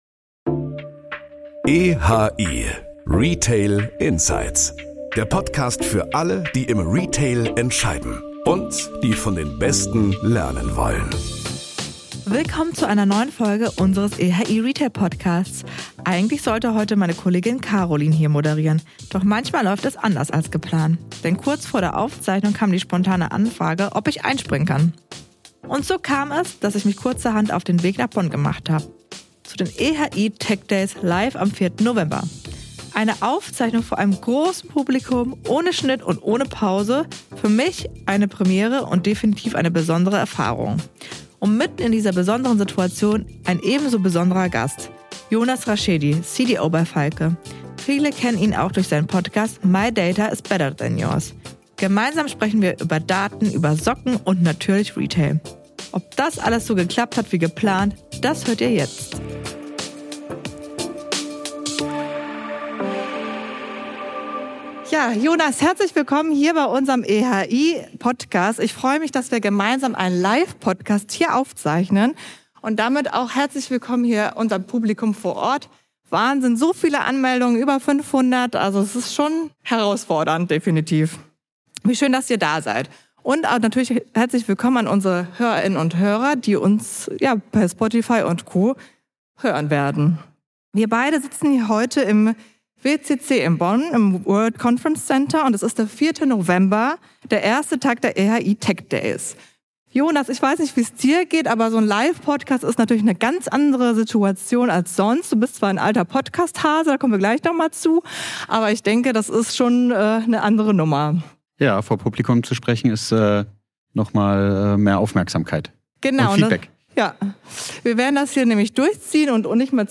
Beschreibung vor 4 Monaten Auf den EHI techdays haben wir am 4. November eine Live-Folge direkt vor Publikum aufgezeichnet.